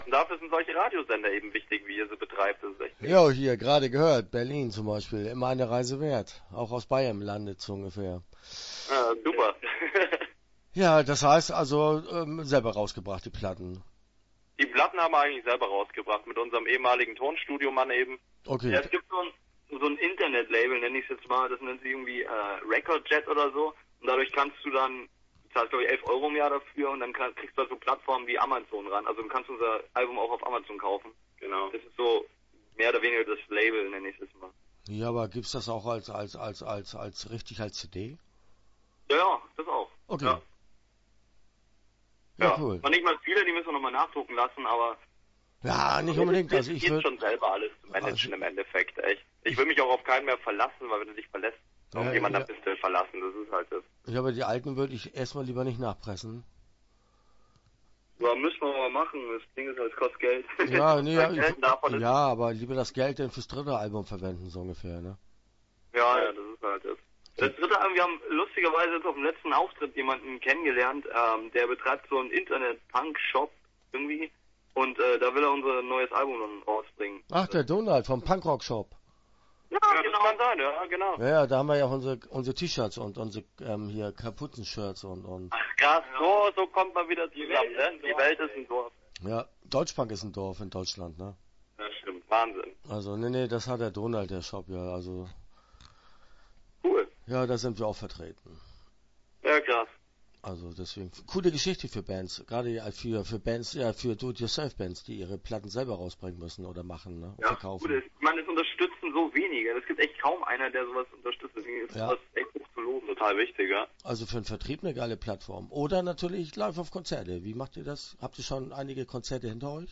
Reisegruppe Hässlich - Interview Teil 1 (7:14)